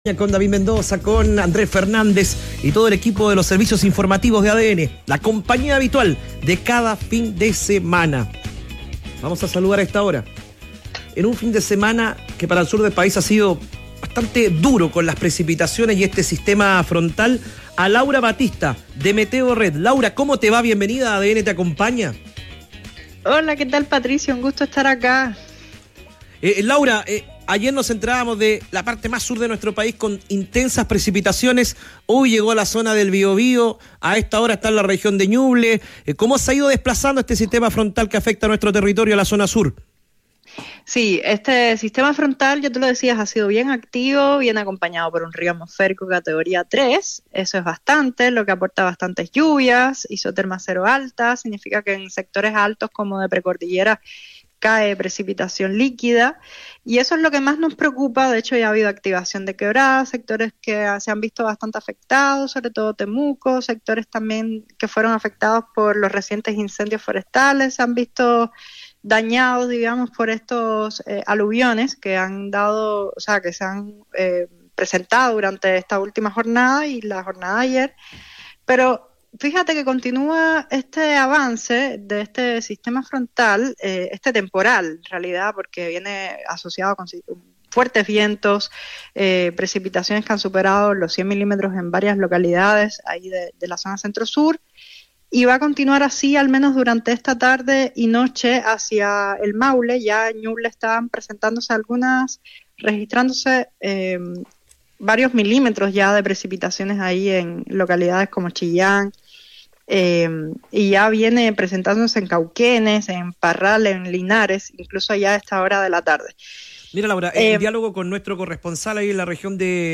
En conversación con ADN Te Acompaña, experta advierte que la isoterma elevada provoca caída líquida en altura, aumentando peligro de remociones.